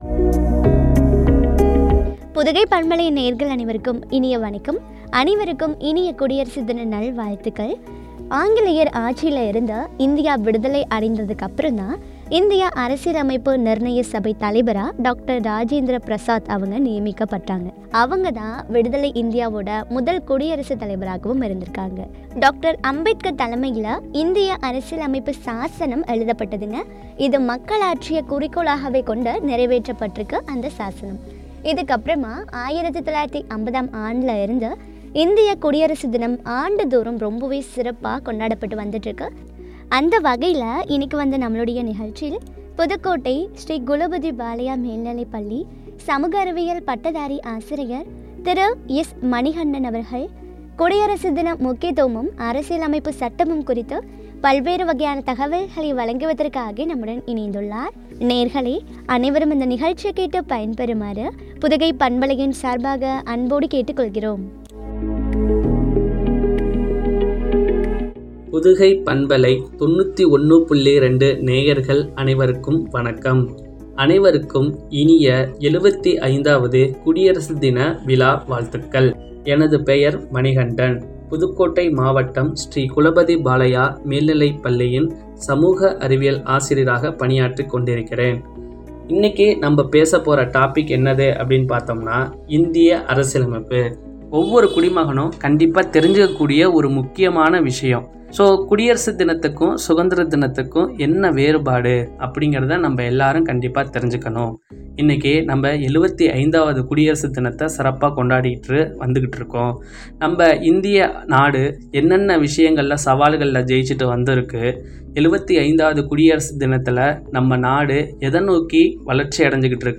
அரசியலமைப்பு சட்டமும்” குறித்த வழங்கிய உரையாடல்